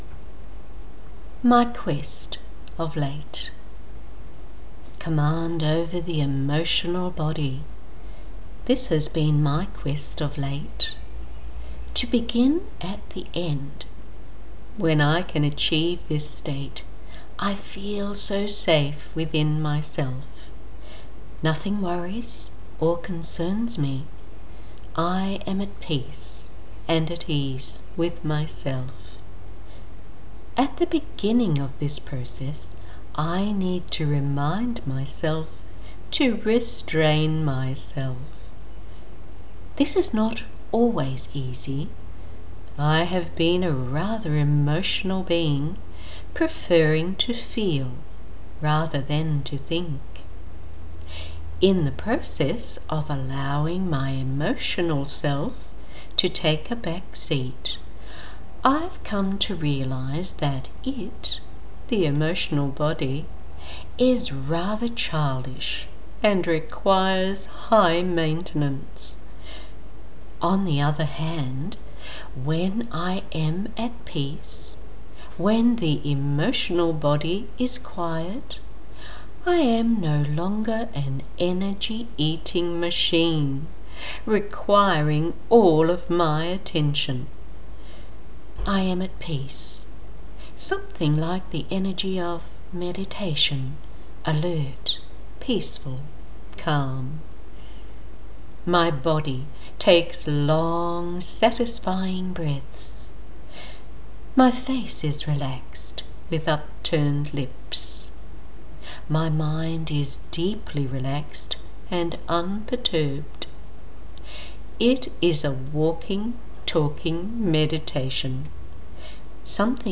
Very peaceful and introspective!.